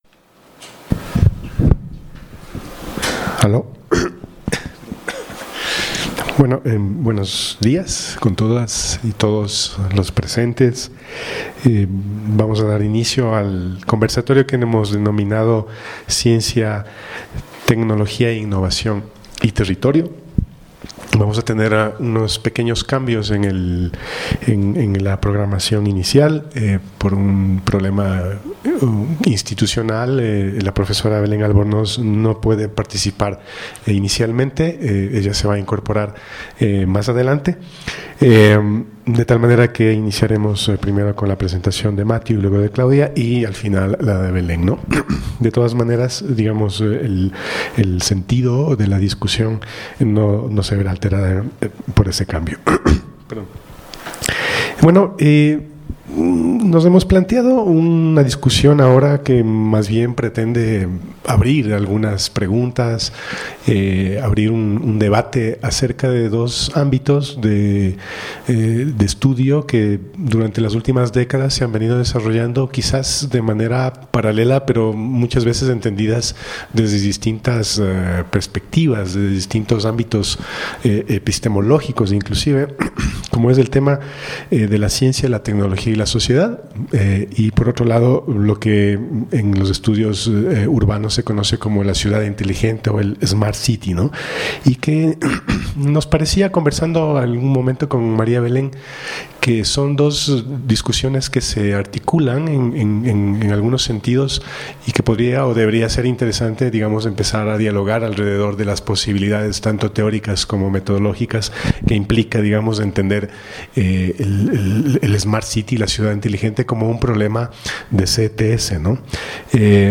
CONVERSATORIO